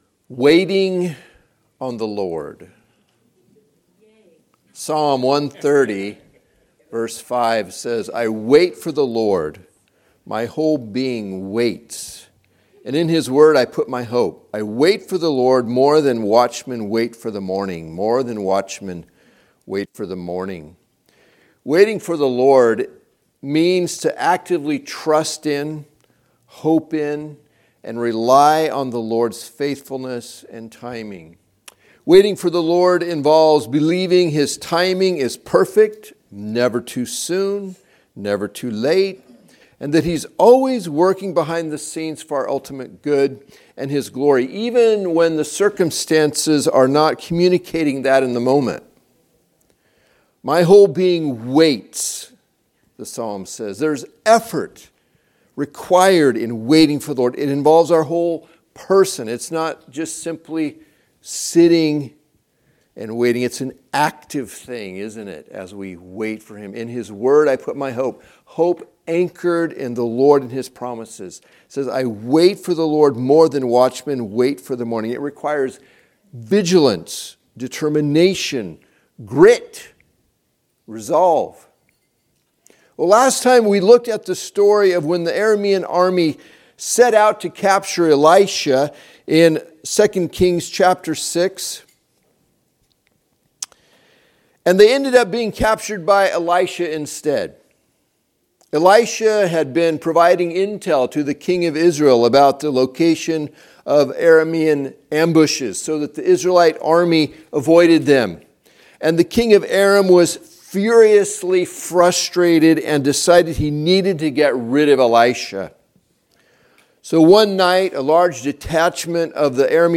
Teaching from Sunday AM service